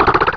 Cri de Méditikka dans Pokémon Rubis et Saphir.
Cri_0307_RS.ogg